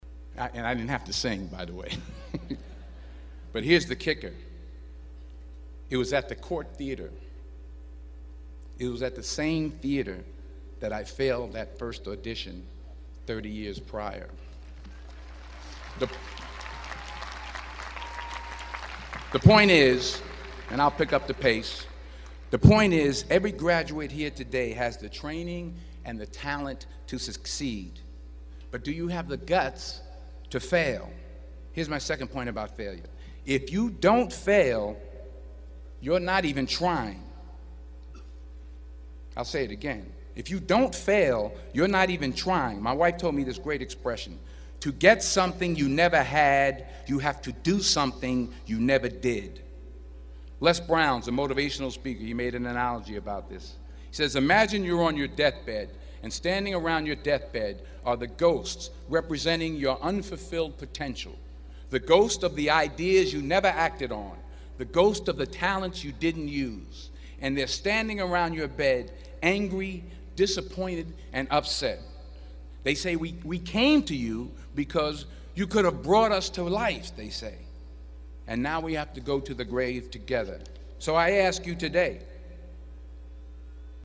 公众人物毕业演讲第426期:丹泽尔2011宾夕法尼亚大学(10) 听力文件下载—在线英语听力室